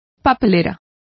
Complete with pronunciation of the translation of bins.